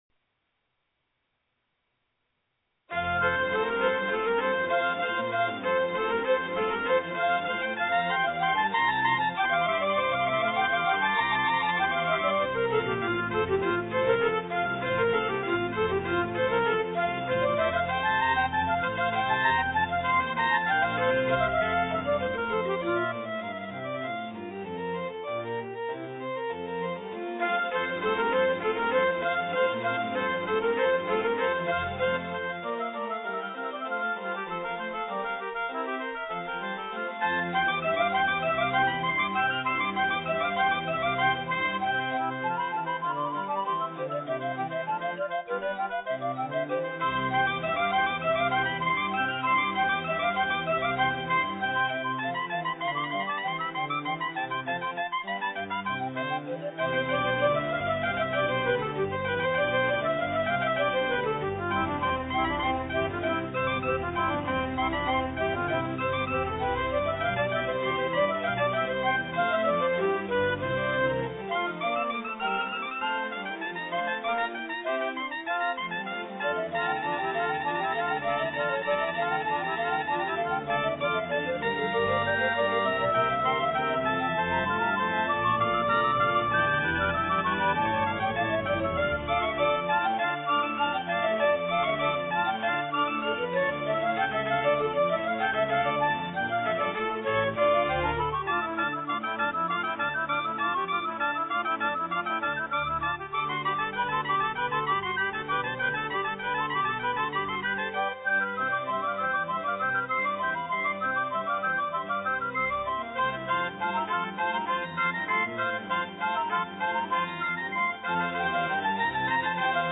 スコアを元に、パート毎に音色を割り振り、重ね録音しました。最大８パートあり、実際はこんなに弾けるわけはありません。
ちょっとしたこだわりで、あくまで自分の指で鍵盤を弾きました。
トランペット、リコーダー、オーボエ、バイオリンの掛け合いが絶妙です。
この音に近い音色が私の電子ピアノにはなくてちょっと不満です。